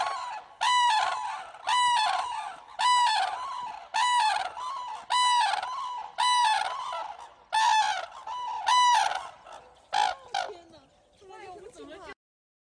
丹顶鹤鸣叫声